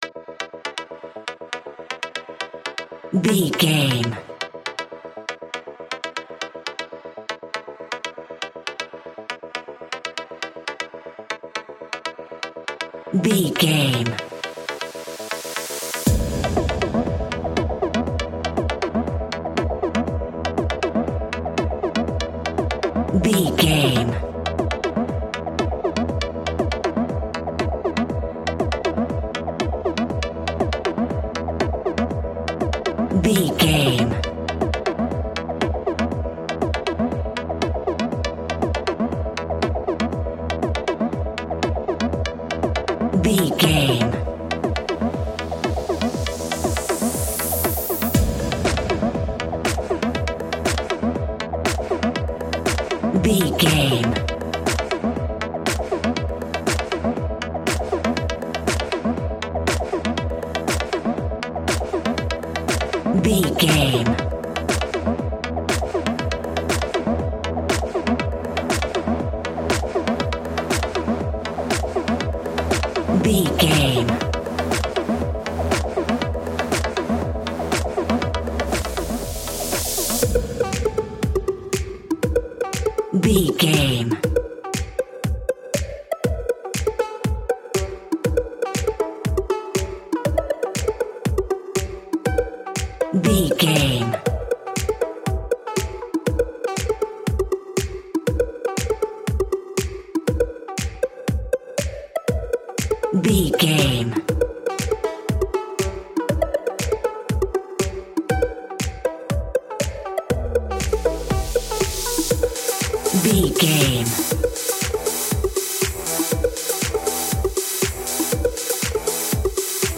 Top 40 Chart Electronic Dance Music Alt.
Atonal
groovy
dreamy
smooth
futuristic
drum machine
synthesiser
house
electro dance
techno
trance
synth leads
synth bass
upbeat